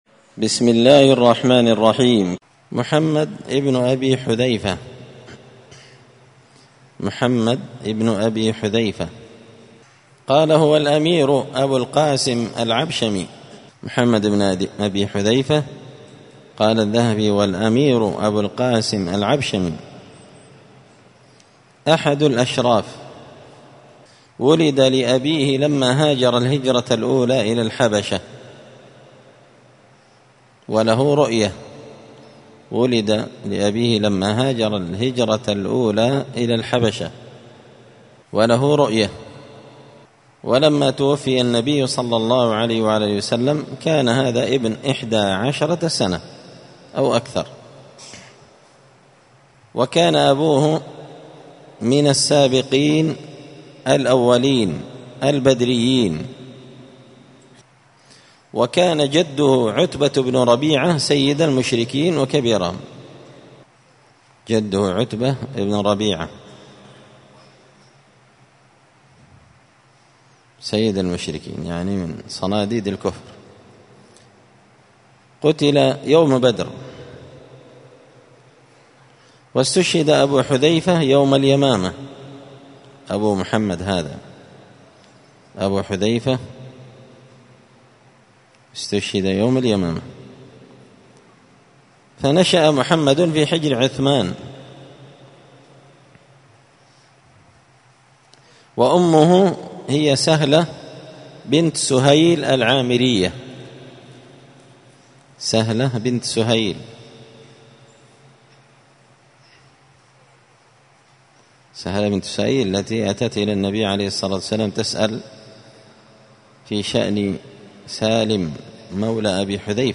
قراءة تراجم من تهذيب سير أعلام النبلاء
مسجد الفرقان قشن المهرة اليمن